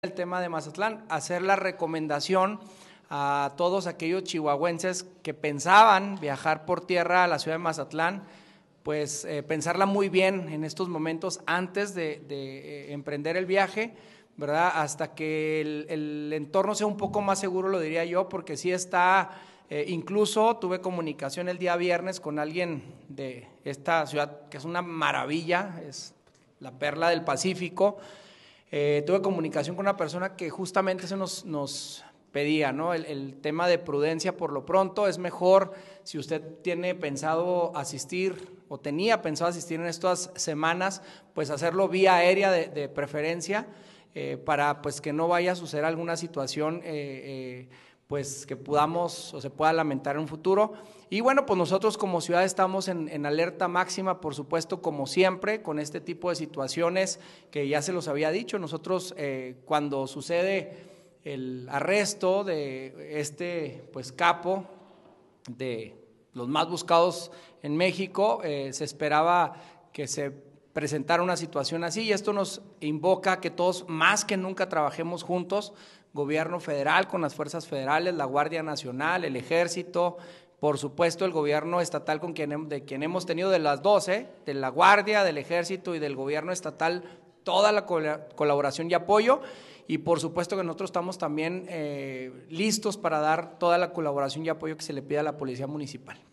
AUDIO: MARCO ANTONIO BONILLA MENDOZA, PRESIDENTE MUNICIPAL DE CHIHUAHUA